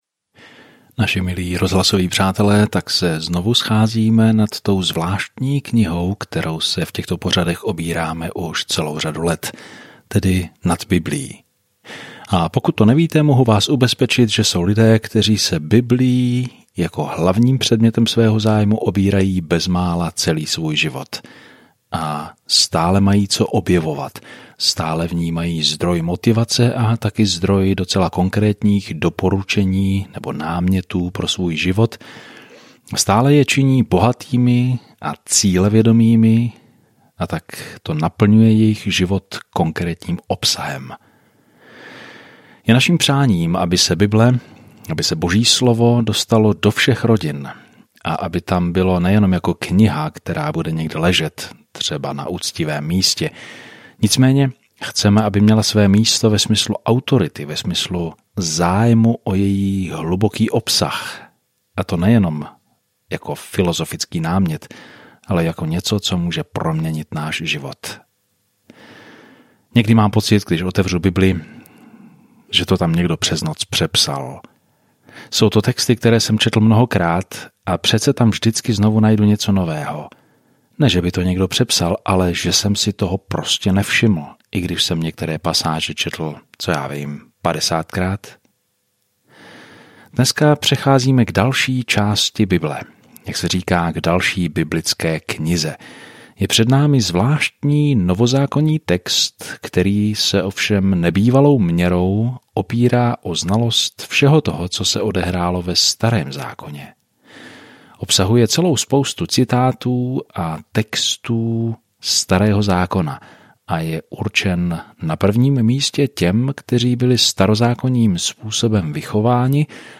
Denně procházejte Hebrejcům, když posloucháte audiostudii a čtete vybrané verše z Božího slova.